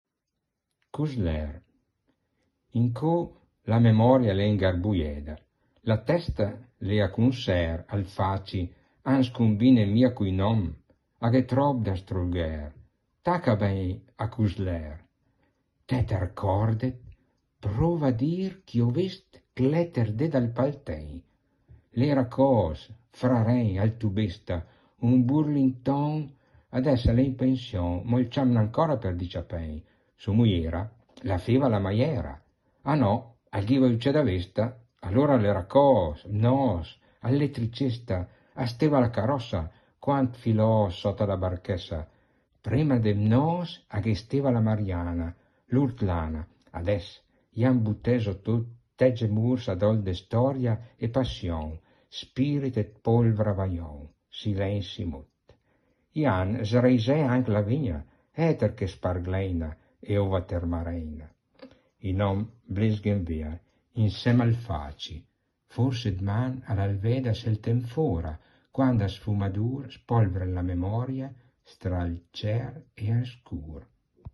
Versione in dialetto recitata